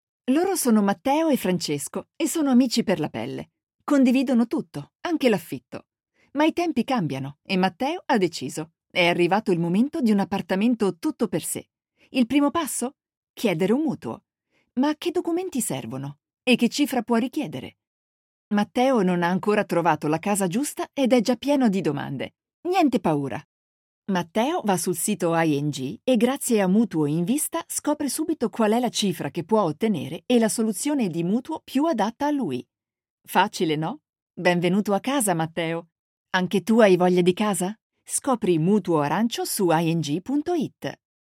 Profi-Sprecherin Italienisch Muttersprache
Sprechprobe: Sonstiges (Muttersprache):
female voice over talent italian mother tongue